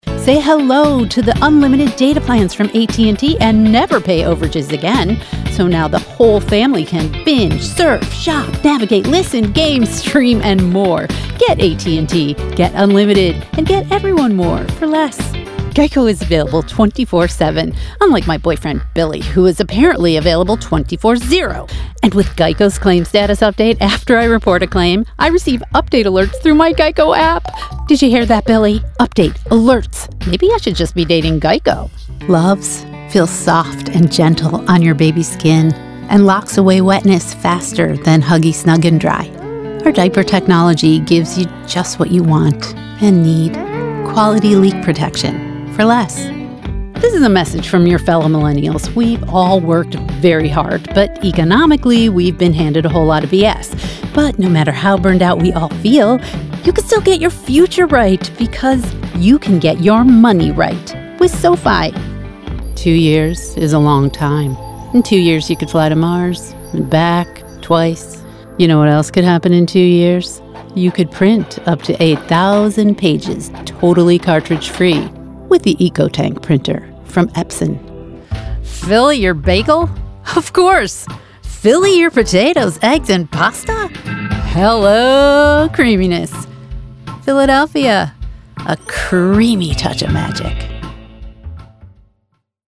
Authentic, quirky, relatable, energetic VO artist, improviser and actor.
Commercial Demo
Irish, British RP, Transatlantic, Southern US